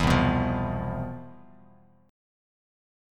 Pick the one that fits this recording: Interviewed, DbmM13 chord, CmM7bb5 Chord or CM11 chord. CM11 chord